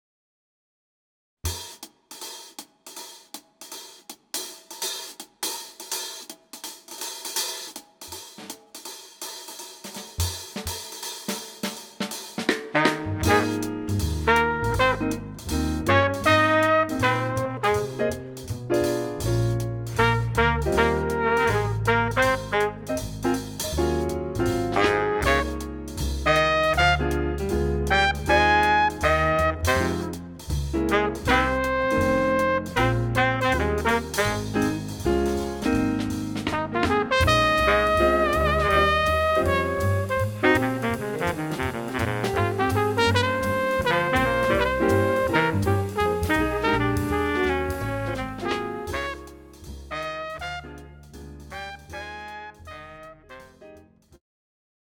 The Best In British Jazz
Recorded at Clowns Pocket Studio, London 2013